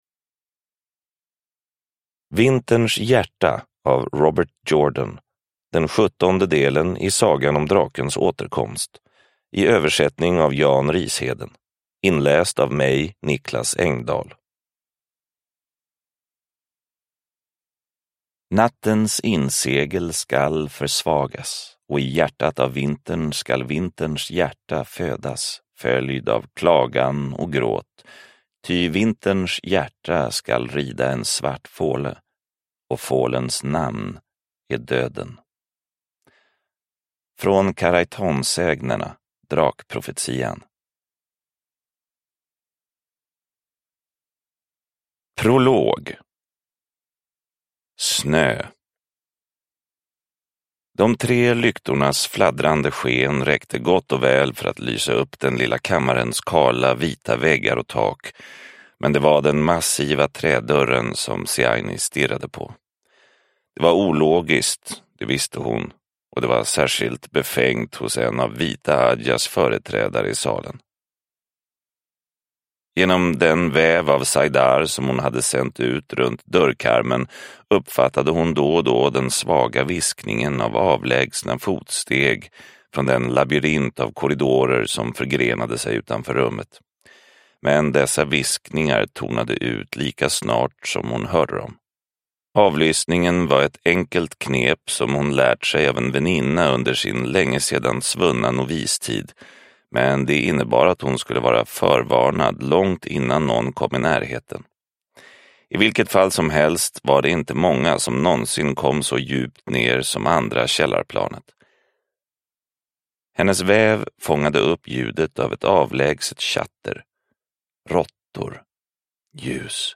Vinterns hjärta – Ljudbok – Laddas ner